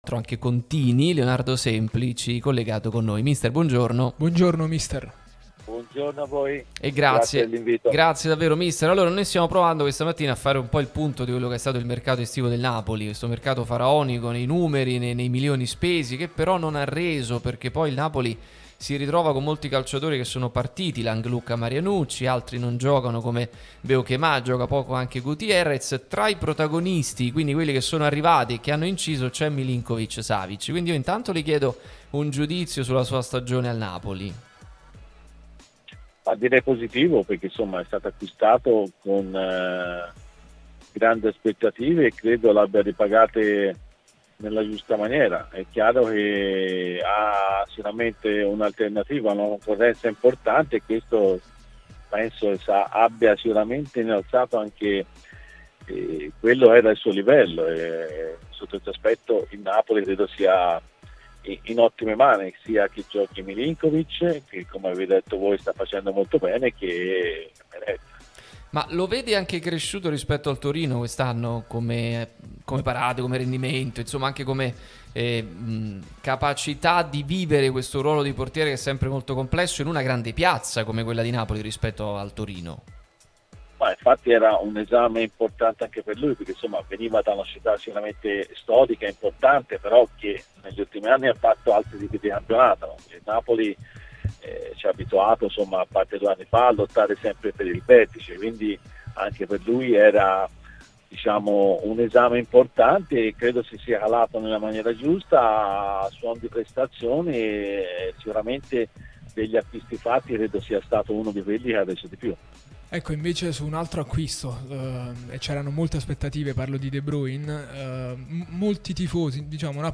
Leonardo Semplici, ex allenatore di Milinkovic e Meret alla Spal, è intervenuto sulla nostra Radio Tutto Napoli, prima radio tematica sul Napoli, in onda tutto il giorno, che puoi vedere qui sul sito o ascoltare sulle app (qui per Iphone o qui per Android) ed in auto col DAB: